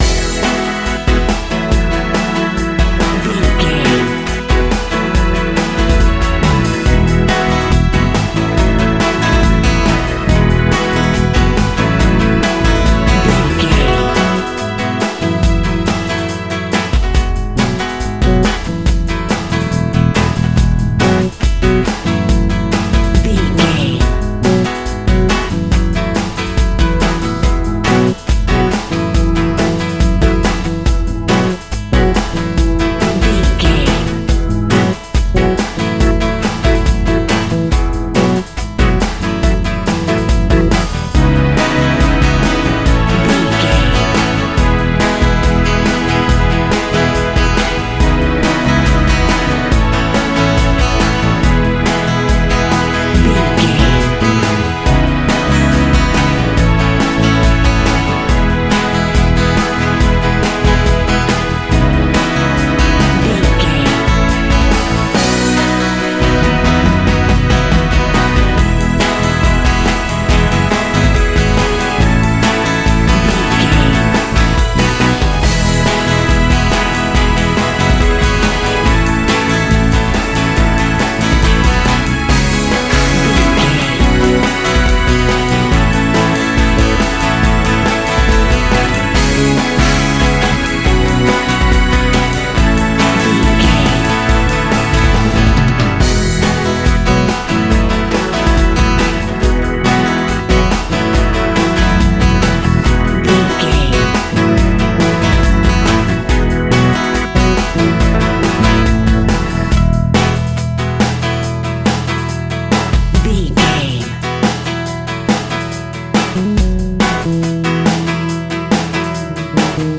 Summertime Pop Rock.
Ionian/Major
indie pop
fun
energetic
uplifting
drums
bass guitar
piano
hammond organ
synth